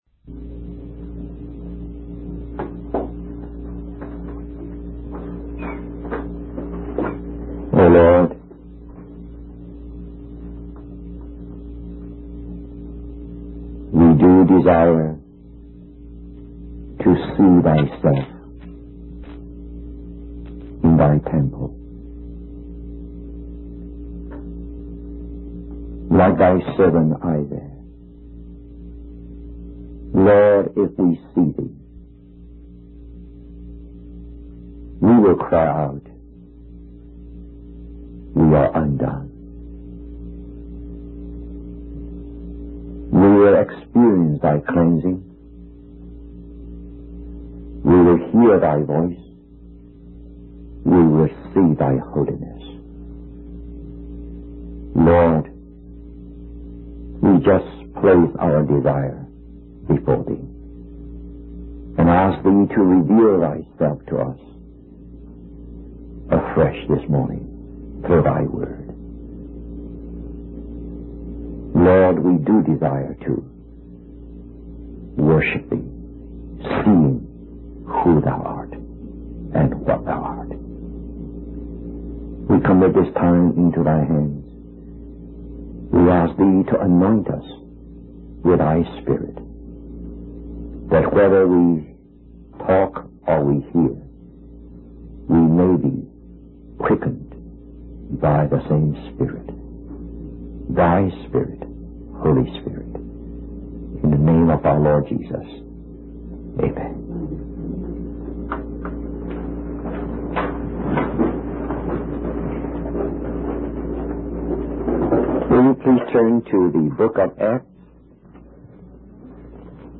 In this sermon on Acts chapter 4, verse 32, the preacher emphasizes the unity and selflessness of the early believers.